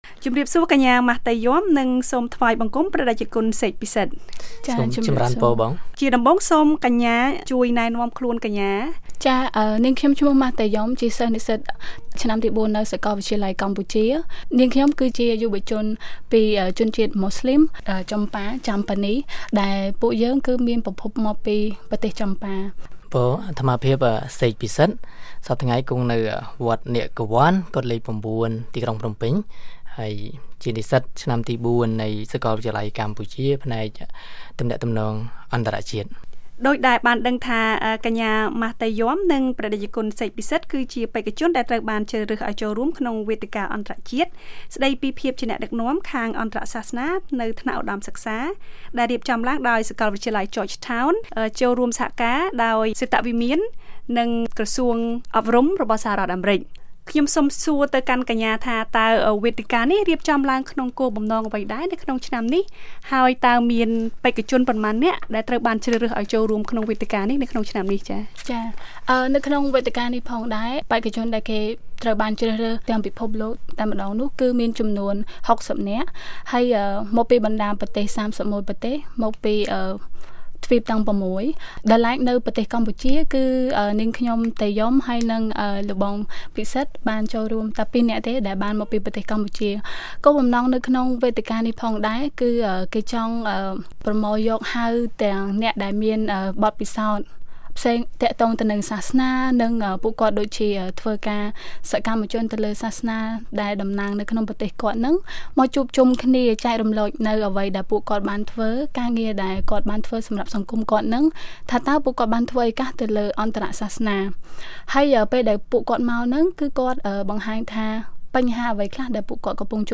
បទសម្ភាសន៍
បទសម្ភាសន៍ VOA៖ វេទិកាអន្តរជាតិស្តីពីភាពជាអ្នកដឹកនាំខាងអន្តរសាសនានៅថ្នាក់ឧត្តមសិក្សា